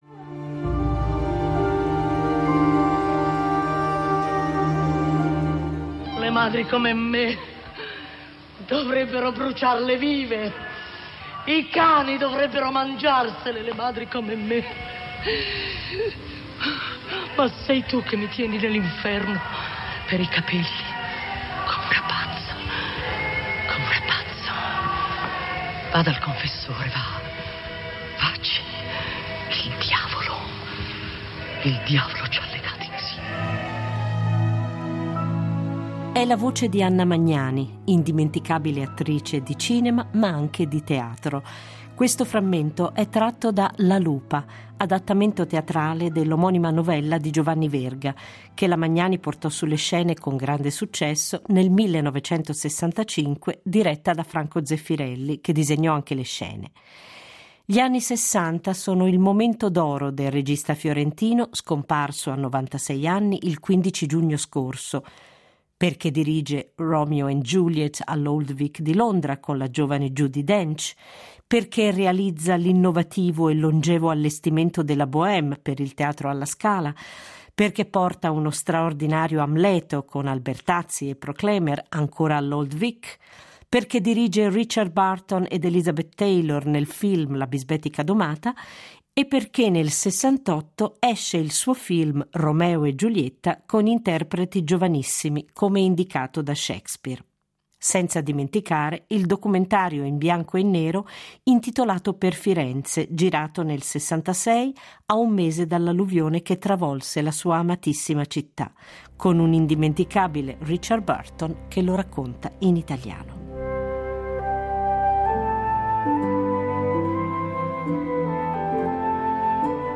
Proponiamo un’intervista di dodici anni fa, in parte inedita, realizzata in occasione dell’uscita della sua “ Autobiografia ” da Mondadori. È un omaggio al regista fiorentino, innamorato della sua città, dove ora riposa.